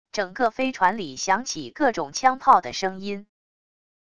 整个飞船里响起各种枪炮的声音wav音频